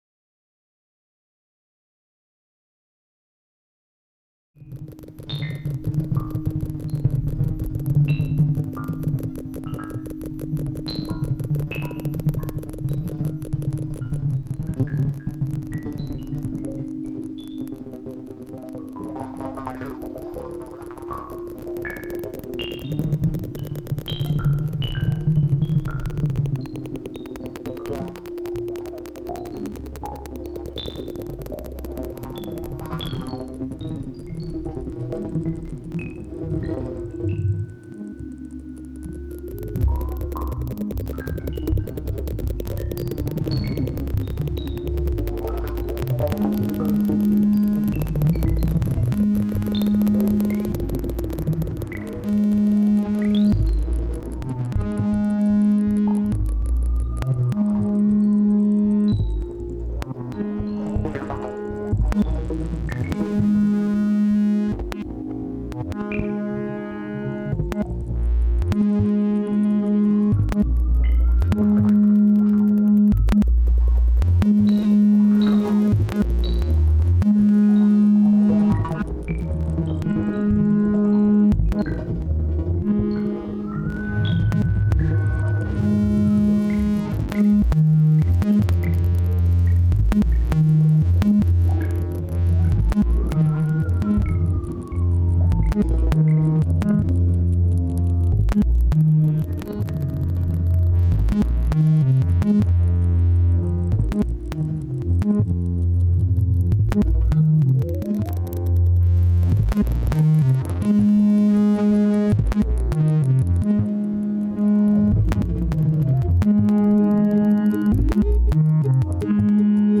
LISTEN TO SOUND: mp3 audio recorded Marseille nov 24th 2010